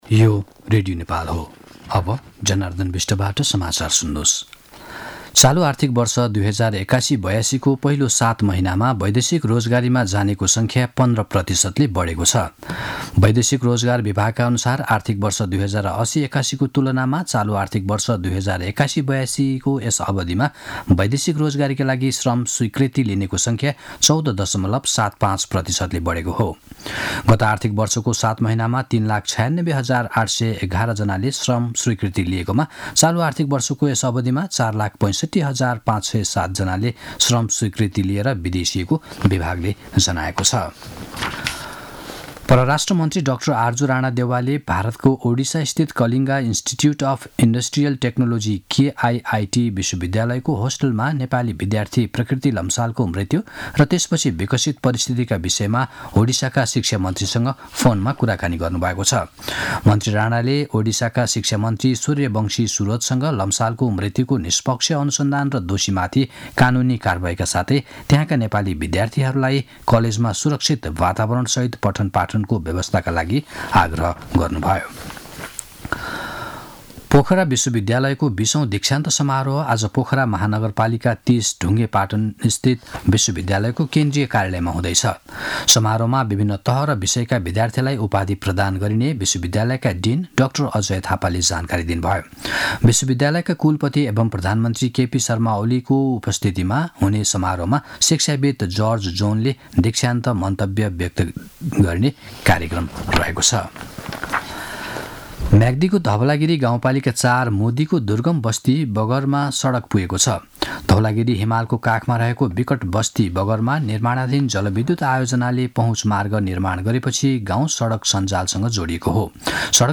दिउँसो १ बजेको नेपाली समाचार : ९ फागुन , २०८१
1-pm-news-6.mp3